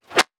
weapon_bullet_flyby_17.wav